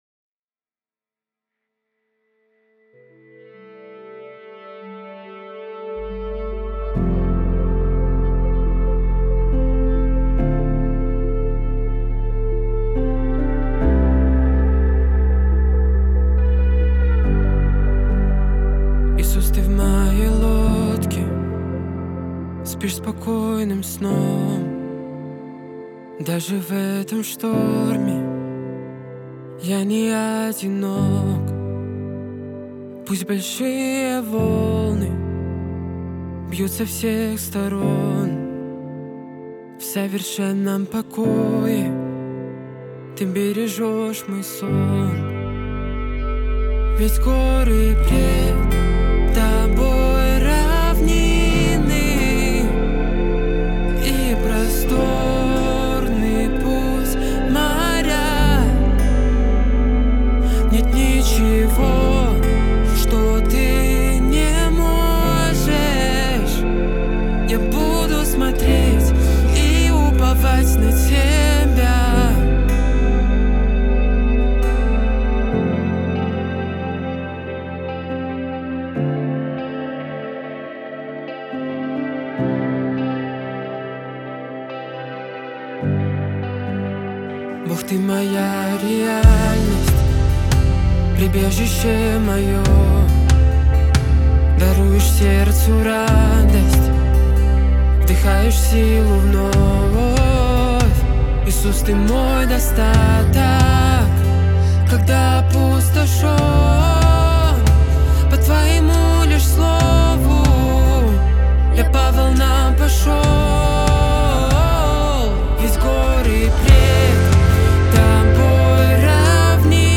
песня
645 просмотров 513 прослушиваний 148 скачиваний BPM: 70